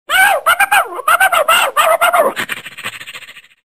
Dino Barking